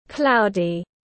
Trời nhiều mây tiếng anh gọi là cloudy, phiên âm tiếng anh đọc là /ˈklaʊ.di/.
Cloudy /ˈklaʊ.di/